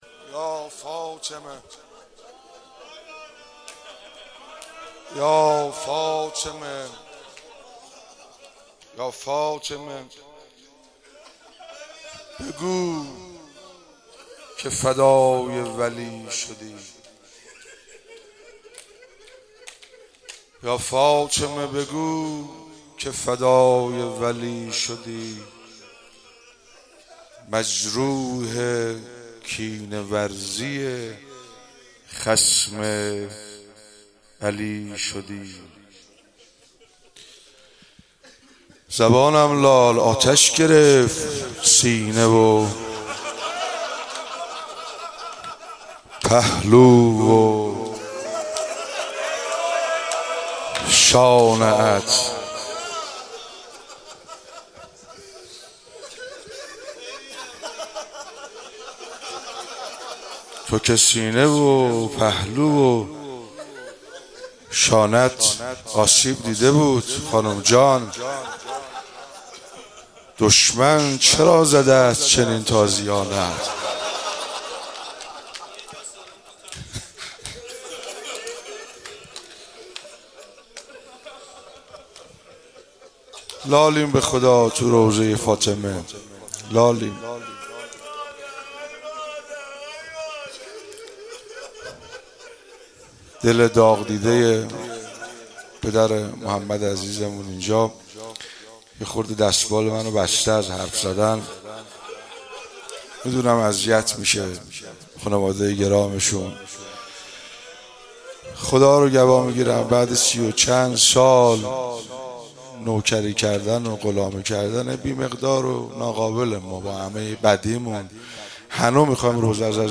2 اسفند 96 - هیئت فاطمیون - روضه